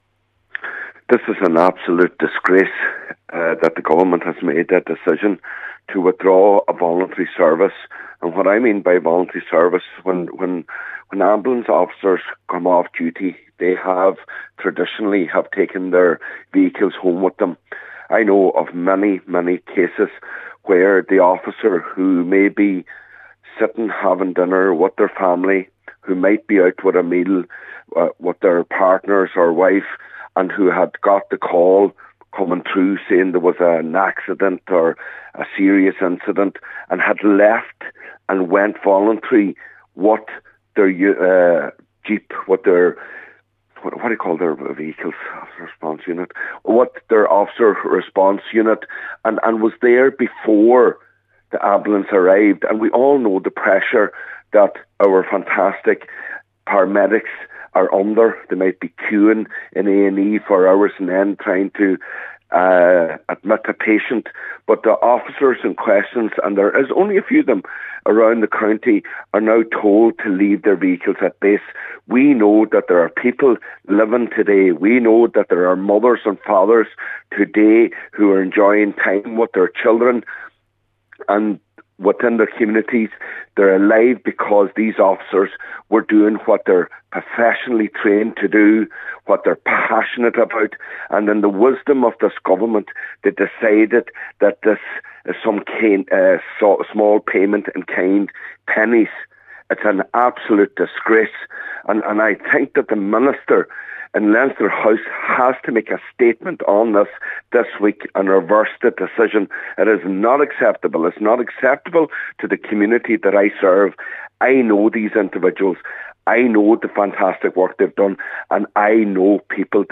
Cllr Micheal Choilm Mac Giolla Easbuig says these response vehicles have saved lives and this move may cost them.